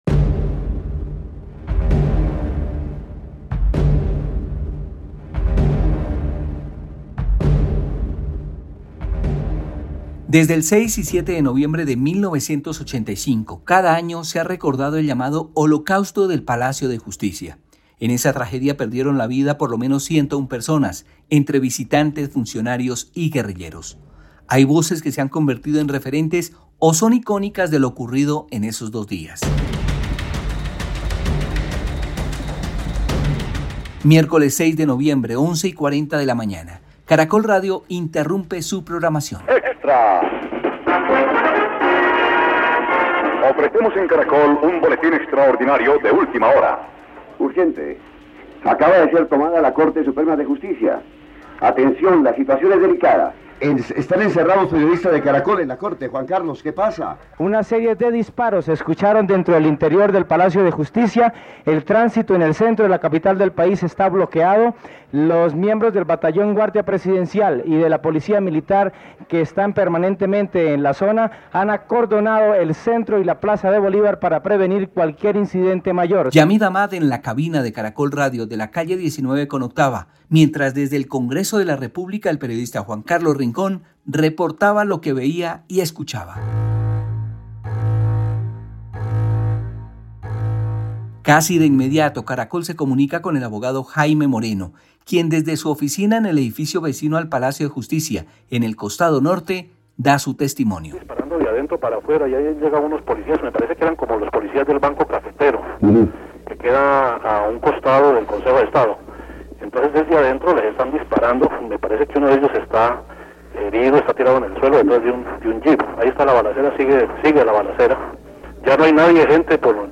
Crónica : 40 años de la toma y retoma del Palacio de Justicia